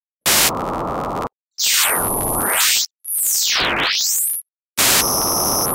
Vertical axis is the frequency (here is from 0 to 11 kHz) while horizontal axis is time.
that is a white noise (white noise is made by uncorrelated superimposition of all frequencies). Instead, if the image contains a horizontal line _ only one frequency will be heard.